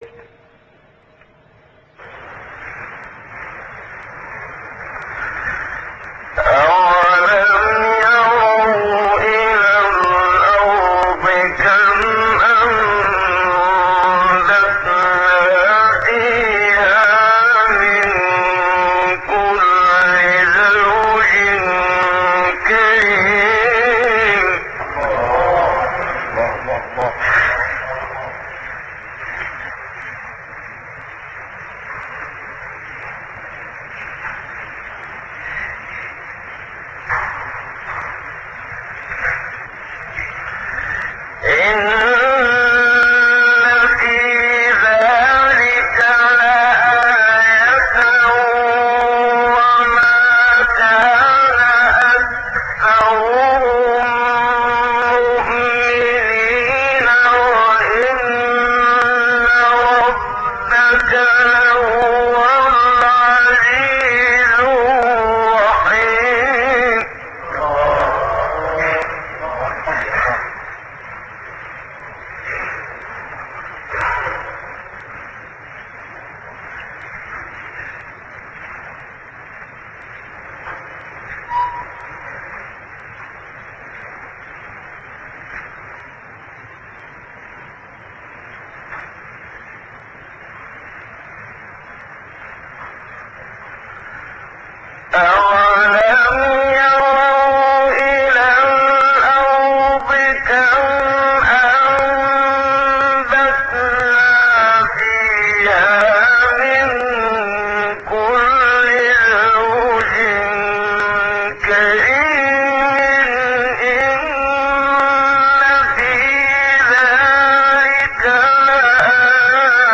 سایت-قرآن-کلام-نورانی-منشاوی-رست-1.mp3